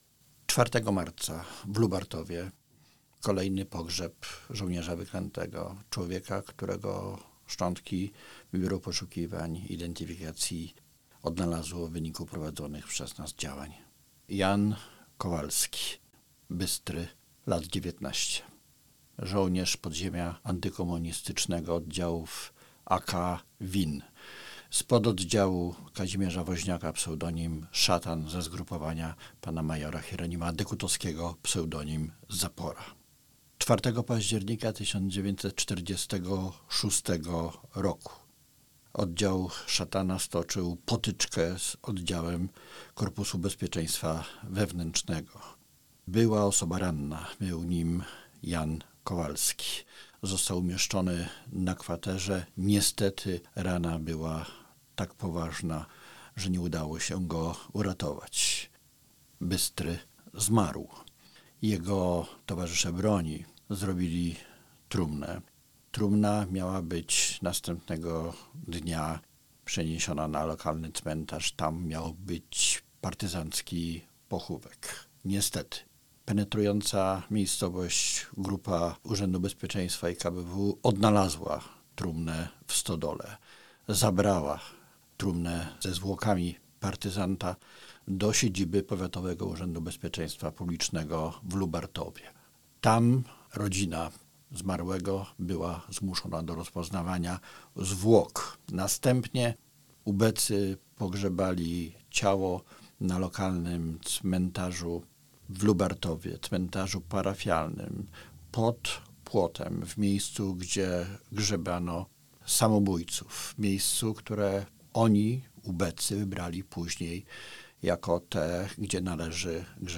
dr hab. Krzysztof Szwagrzyk, zastępca prezesa, pełniący obowiązki dyrektora Biura Poszukiwań i Identyfikacji IPN o pogrzebie Jana Kowalskiego ps. „Bystry” (mp3, 3.66 MB) dr hab.